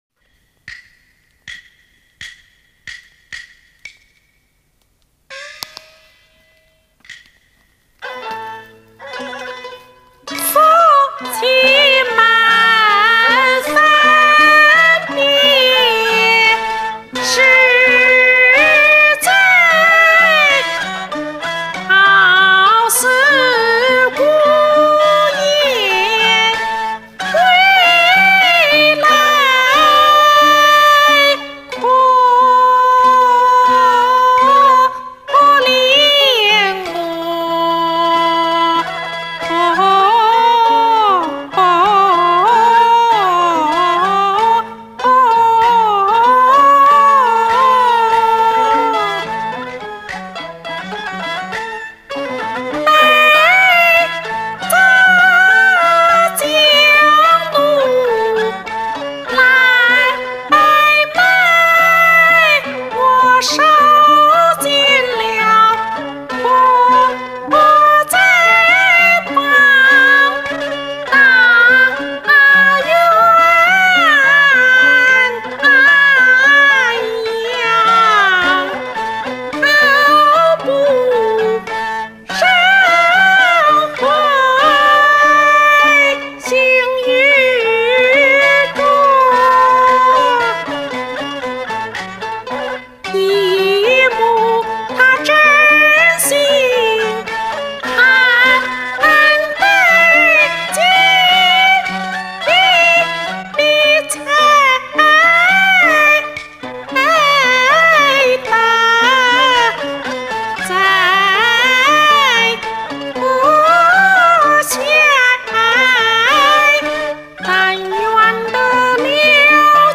旦角就应该这样唱。
韵味十足。